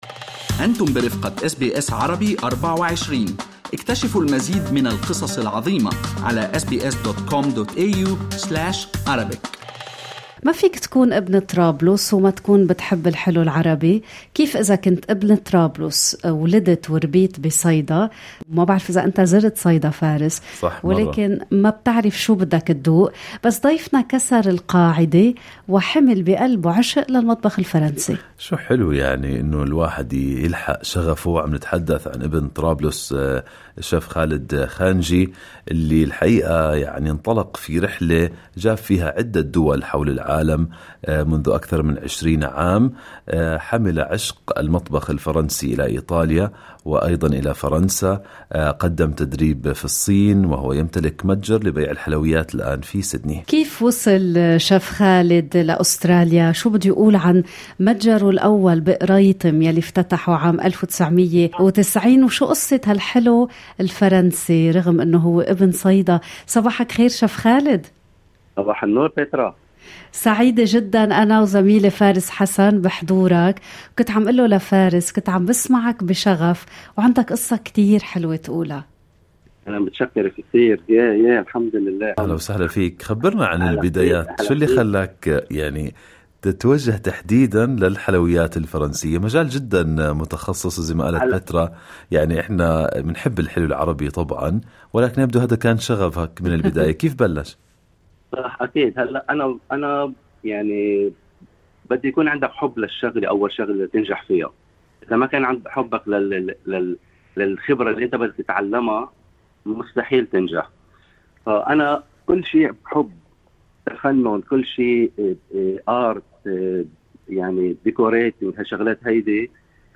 استمعوا إلى اللقاء كاملا في المدونة الصوتية في أعلى الصفحة.